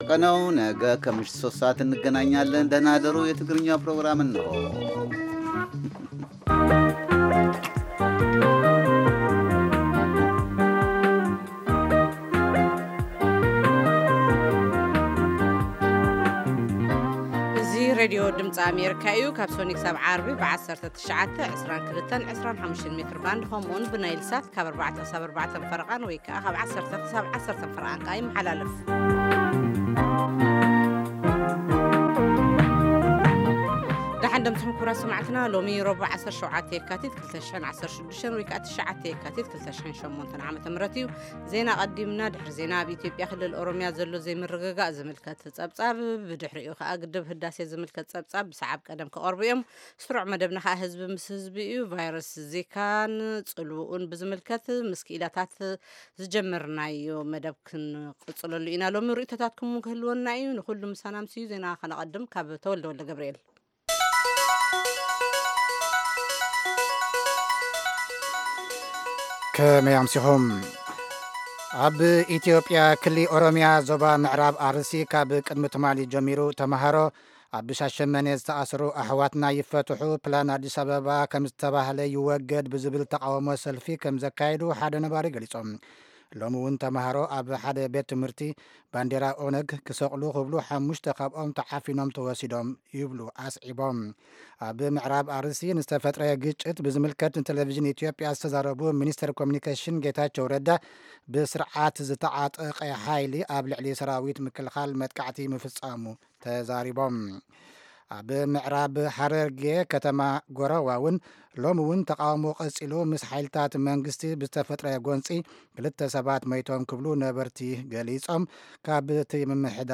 ፈነወ ትግርኛ ብናይ`ዚ መዓልቲ ዓበይቲ ዜና ይጅምር ። ካብ ኤርትራን ኢትዮጵያን ዝረኽቦም ቃለ-መጠይቓትን ሰሙናዊ መደባትን ድማ የስዕብ ። ሰሙናዊ መደባት ረቡዕ፡ ህዝቢ ምስ ህዝቢ